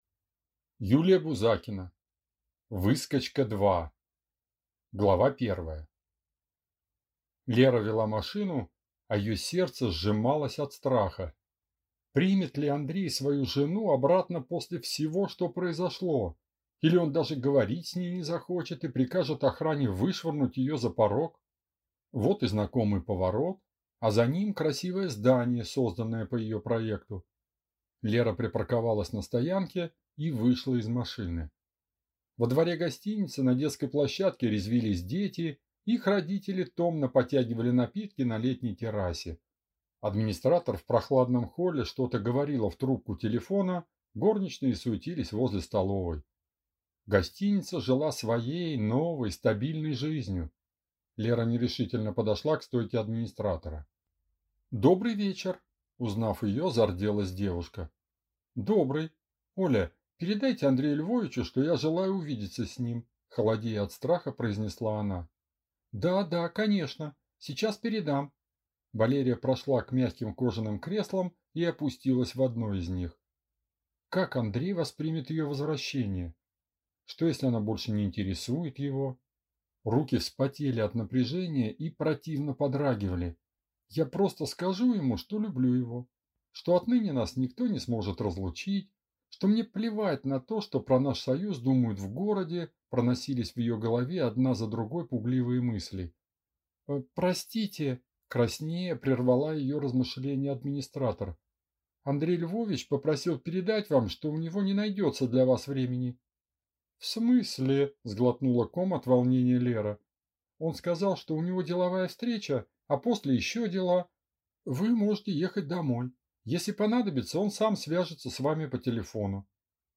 Аудиокнига Выскочка-2 | Библиотека аудиокниг